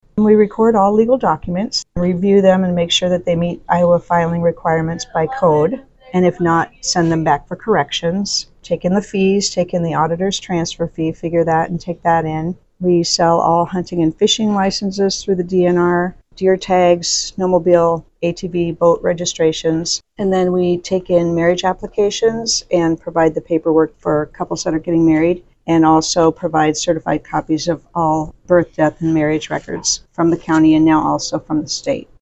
Wilkinson recently spoke with RadioOnTheGo News about her time working as the county recorder and what her office does on a daily basis.